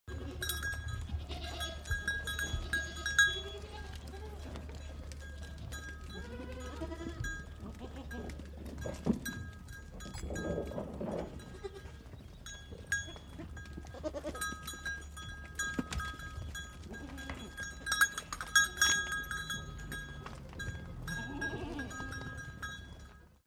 دانلود آهنگ مزرعه 20 از افکت صوتی طبیعت و محیط
دانلود صدای مزرعه 20 از ساعد نیوز با لینک مستقیم و کیفیت بالا
برچسب: دانلود آهنگ های افکت صوتی طبیعت و محیط دانلود آلبوم صدای مزرعه روستایی از افکت صوتی طبیعت و محیط